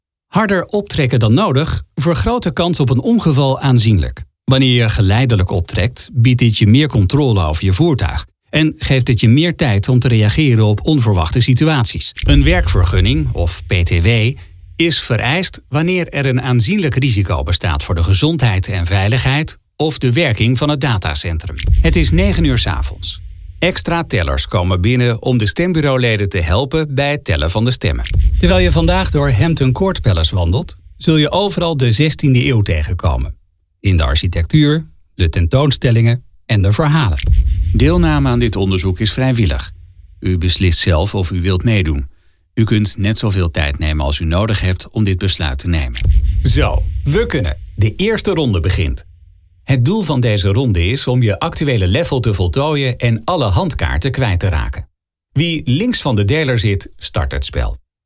Authentic Dutch voice-over with a warm tone, fast delivery and a relaxed, professional approach
Middle Aged